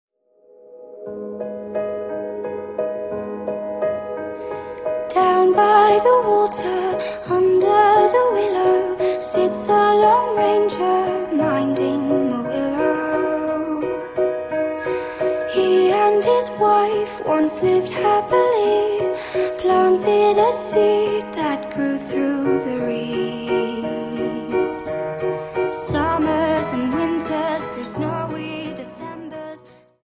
Dance: Viennese Waltz 58